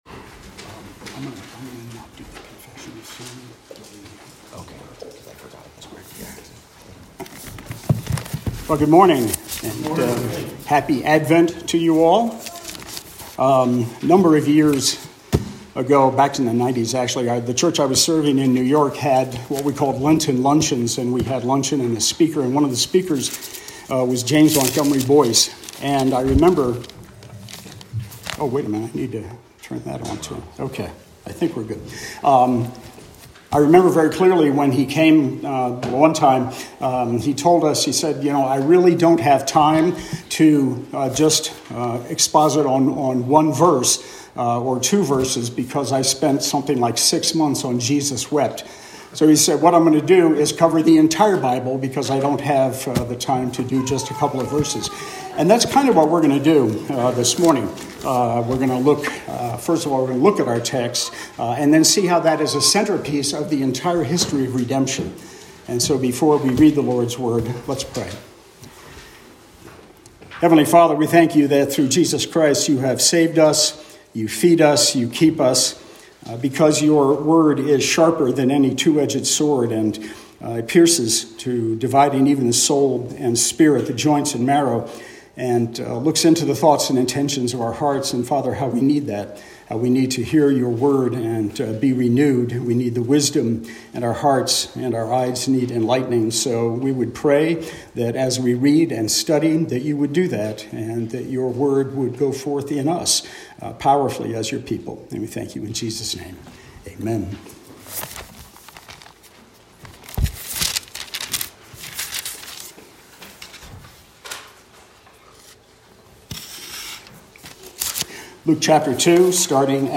Series: Guest Preacher
Service Type: Morning Service